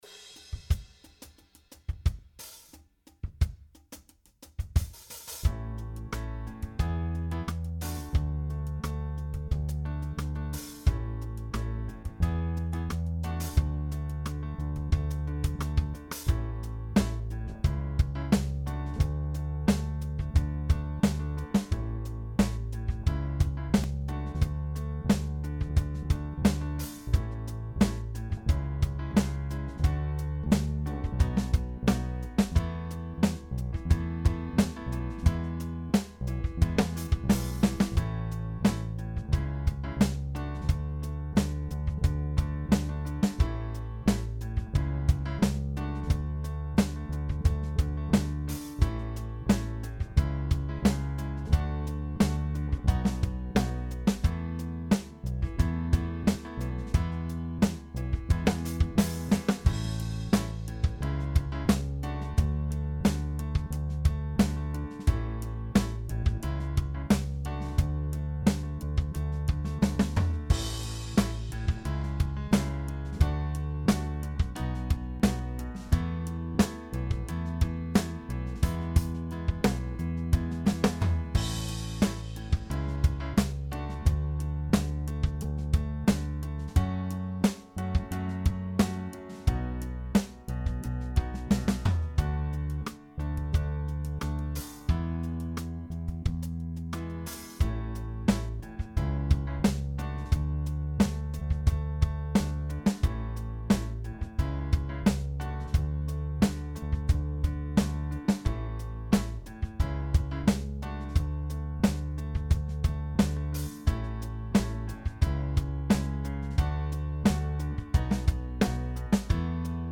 Jam Track
Cover version